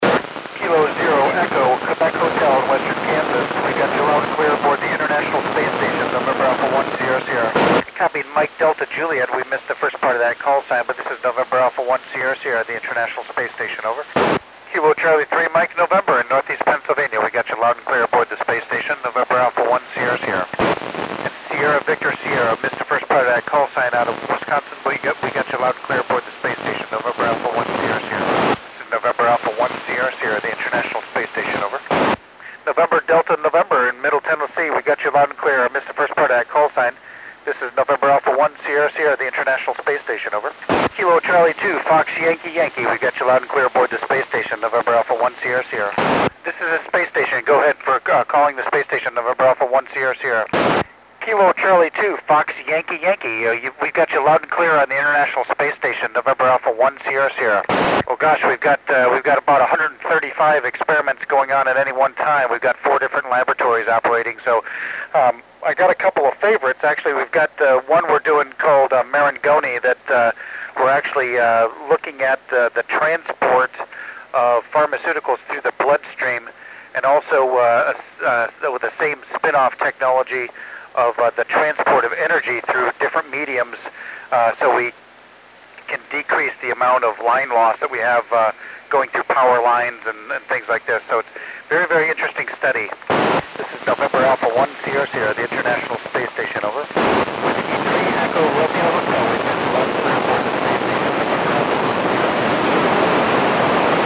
Col. Doug Wheelock (NA1SS) works U.S. stations on 21 September 2010 at 2019 UTC.